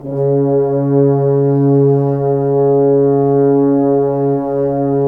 Index of /90_sSampleCDs/Roland LCDP06 Brass Sections/BRS_F.Horns 2 mf/BRS_FHns Dry mf